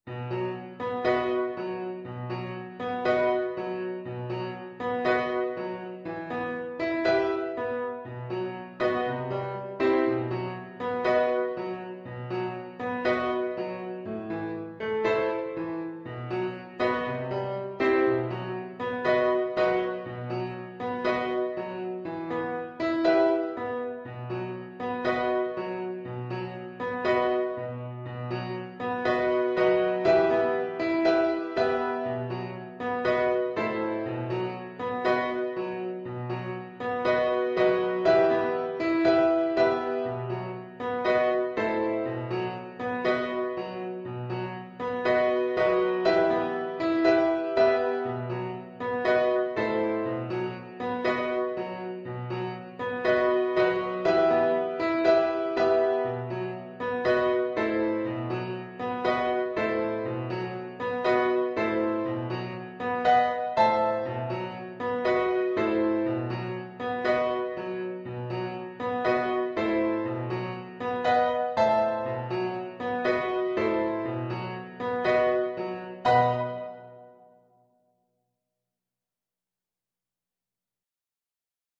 Play (or use space bar on your keyboard) Pause Music Playalong - Piano Accompaniment Playalong Band Accompaniment not yet available reset tempo print settings full screen
E minor (Sounding Pitch) (View more E minor Music for Viola )
Allegro moderato =120 (View more music marked Allegro)
4/4 (View more 4/4 Music)